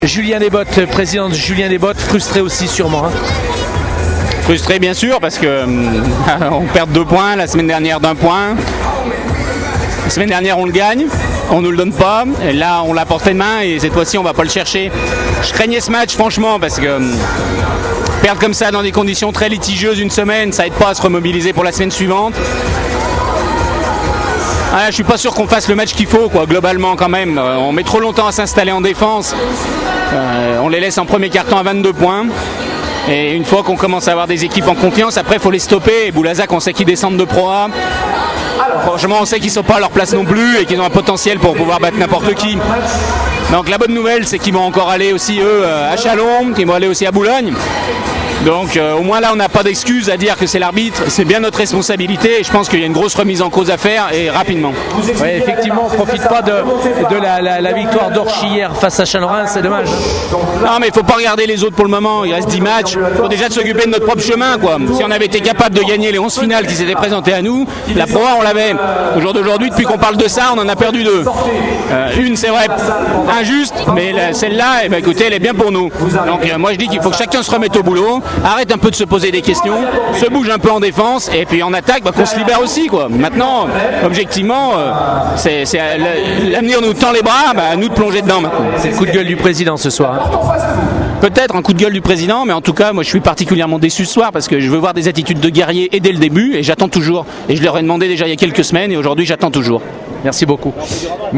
réactions d’après-match